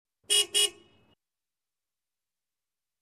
Звуки гудка автомобиля
Звук двойного гудка грузового сигнала